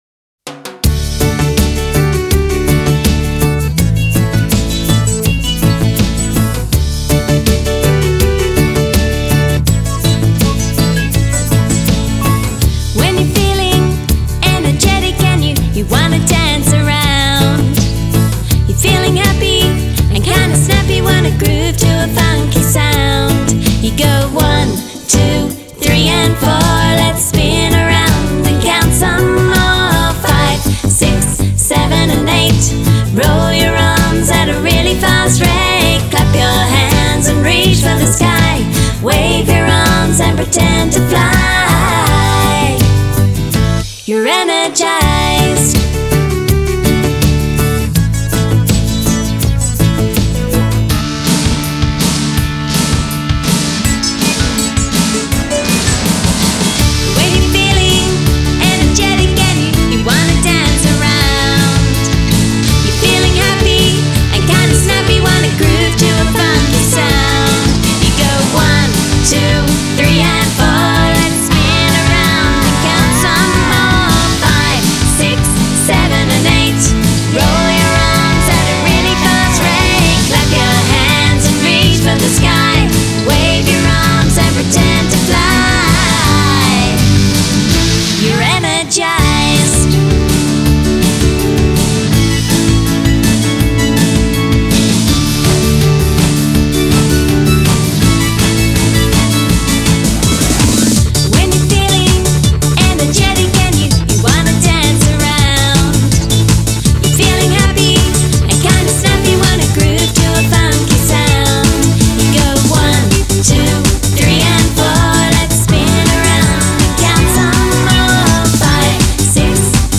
Genre: Children.